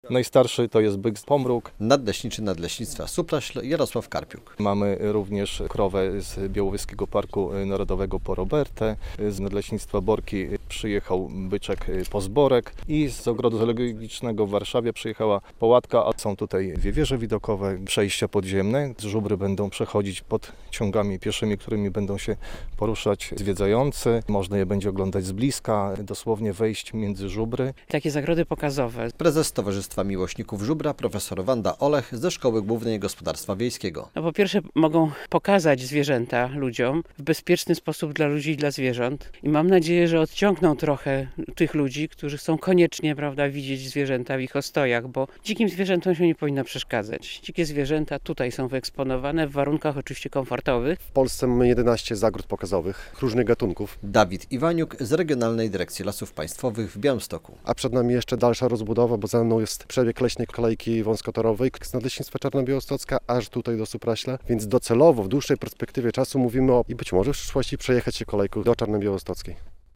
W podbiałostockiej Kopnej Górze powstała druga w Podlaskiem zagroda pokazowa żubrów - relacja